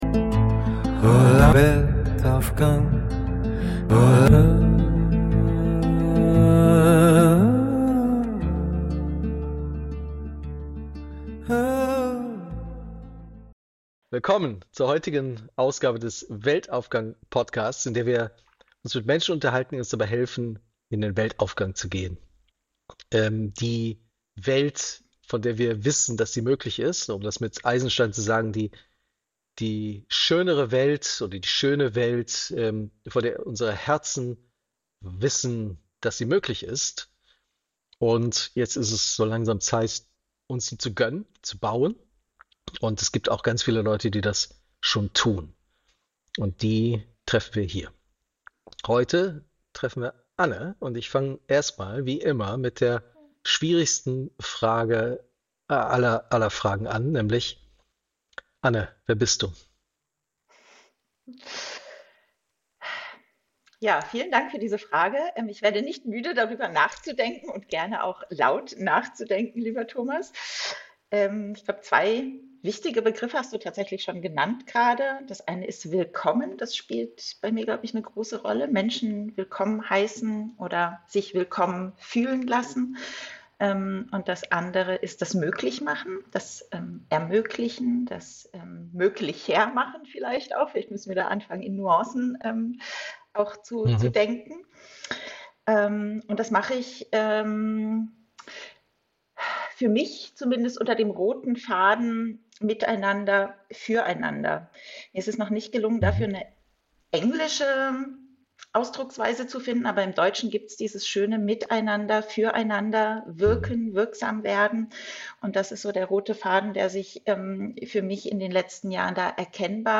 Das Gespräch vertieft sich in das Konzept der „Warm Data“ (nach Nora Bateson): Es geht darum, nicht nur isolierte Datenpunkte zu betrachten, sondern die lebenswichtigen Informationen in den Zwischenräumen und Beziehungen eines Systems wahrzunehmen.